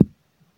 beeb kick 23
Tags: 808 drum cat kick kicks hip-hop